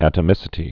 (ătə-mĭsĭ-tē)